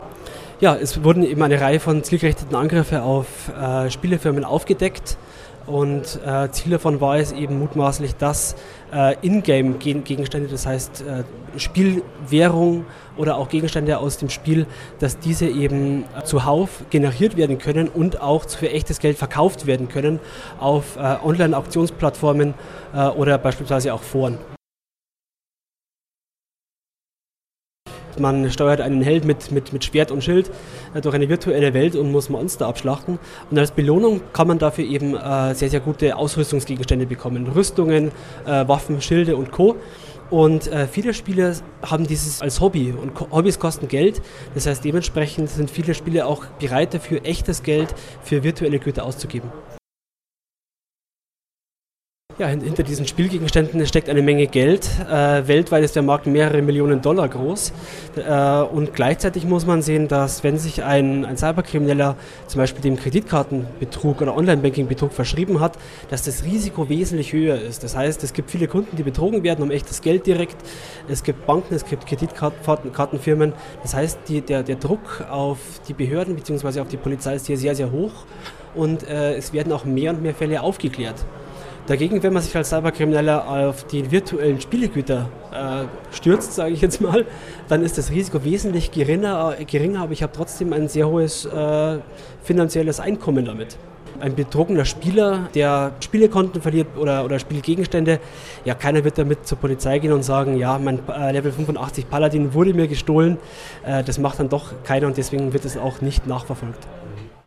Kollegengespräch: Schadprogramme attackieren Gamer